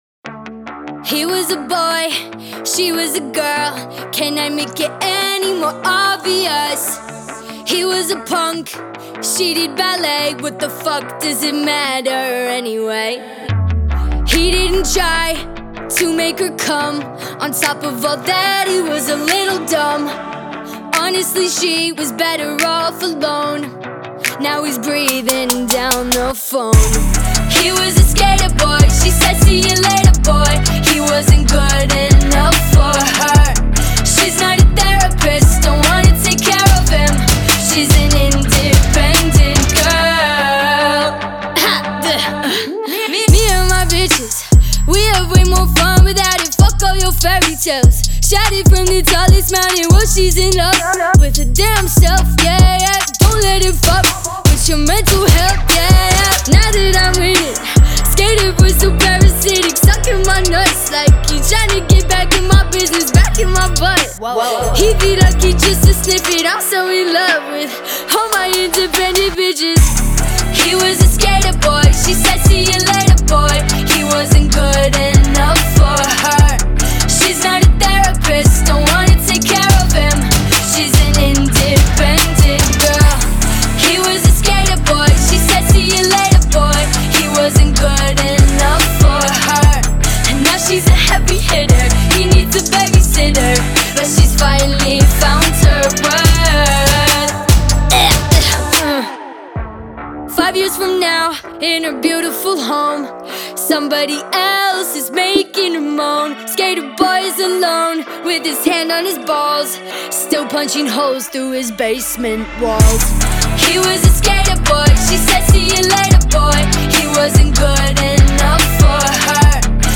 энергичная поп-панк песня